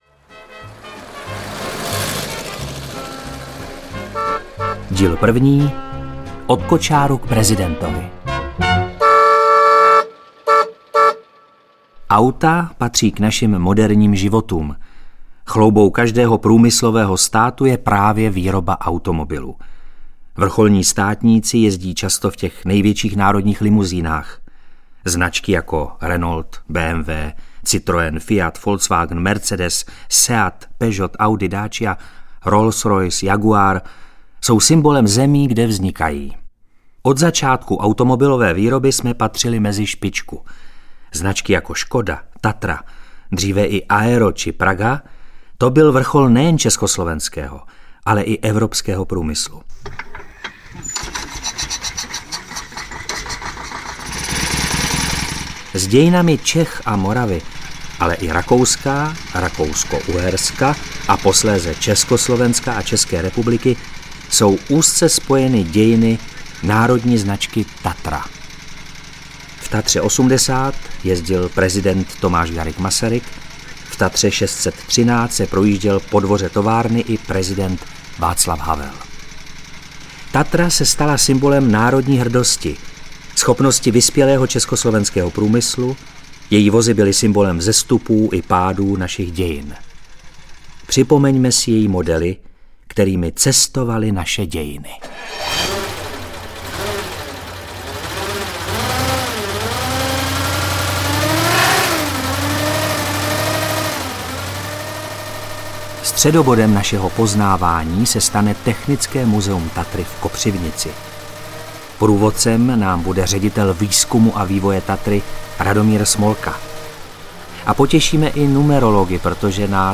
Reportážní čtyřdílný seriál Českého rozhlasu popisuje zvukově bohatou formou rozhodující okamžiky českých dějin v souběhu s dějinami slavné automobilky Tatra.
Ukázka z knihy